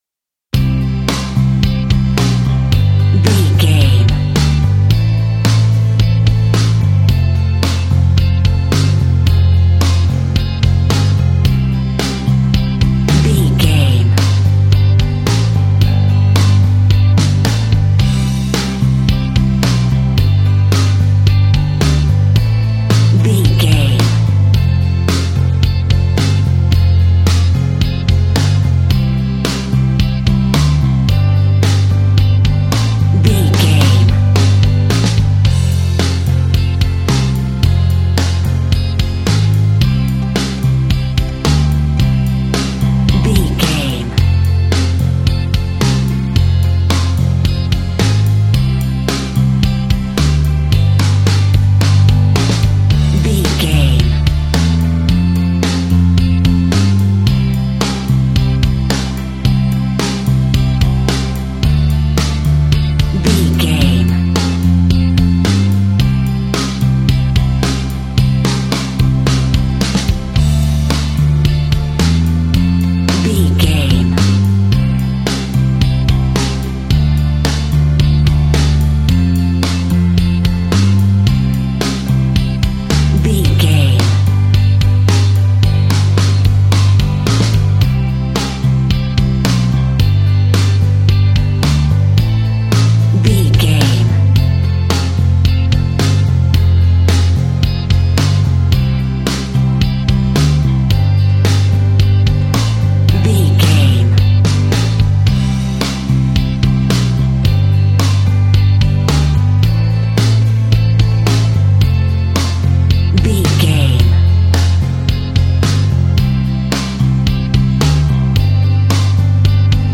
Ionian/Major
calm
melancholic
happy
energetic
smooth
uplifting
electric guitar
bass guitar
drums
pop rock
indie pop
instrumentals
organ